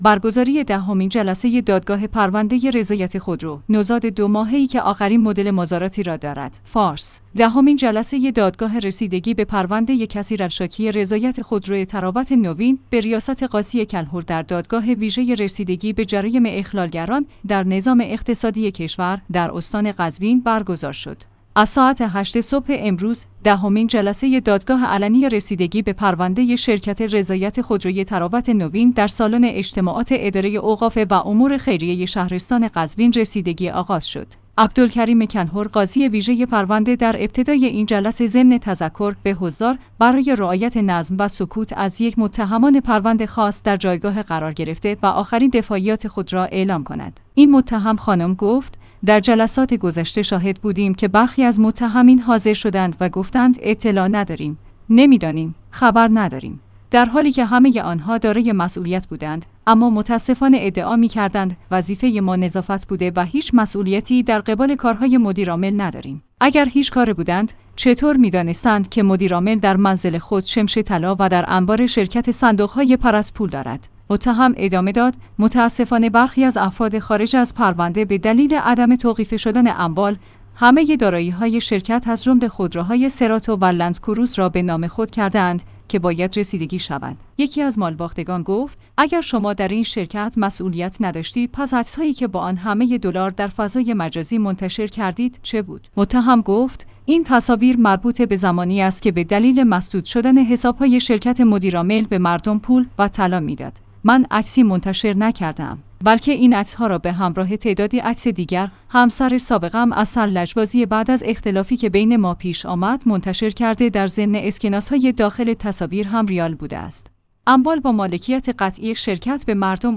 فارس/ دهمین جلسه دادگاه رسیدگی به پرونده کثیرالشاکی رضایت خودرو طراوت نوین به ریاست قاضی کلهر در دادگاه ویژه رسیدگی به جرایم اخلالگران در نظام اقتصادی کشور در استان قزوین برگزار شد.